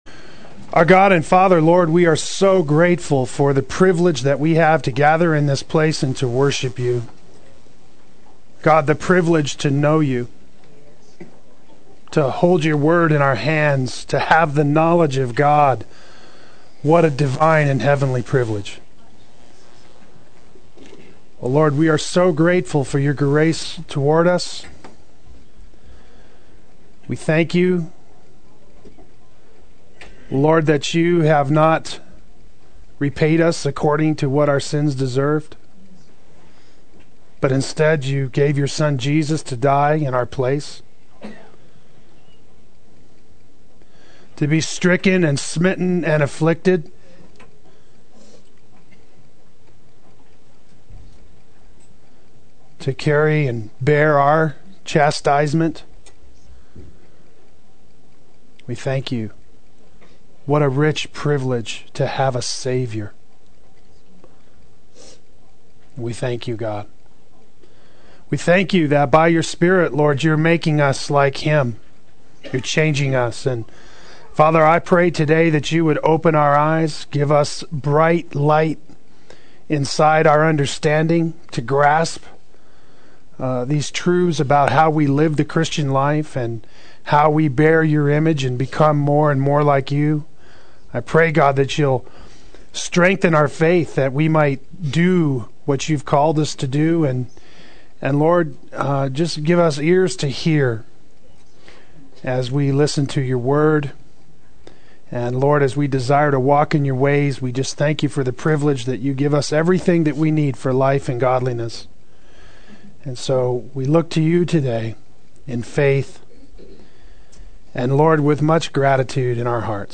Play Sermon Get HCF Teaching Automatically.
Killing Pride and Taking Thoughts Captive Adult Sunday School